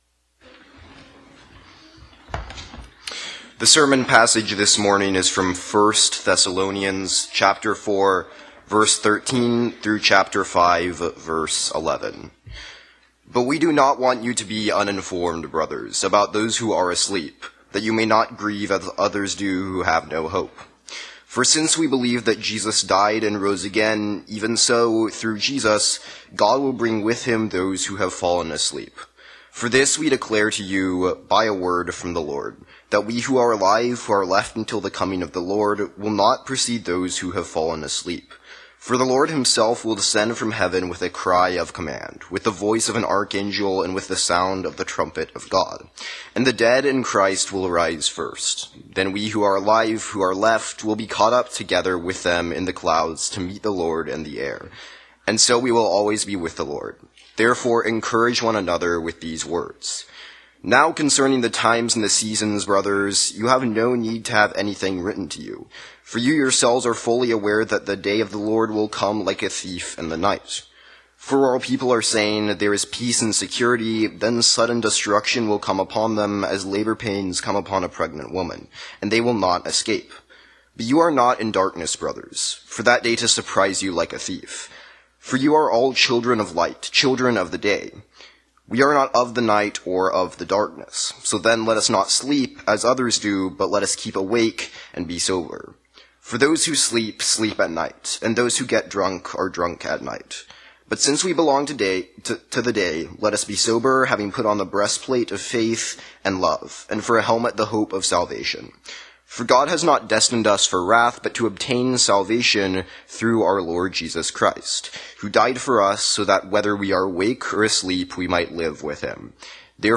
Sermons from Christ the King Presbyterian Church (PCA) in Austin, TX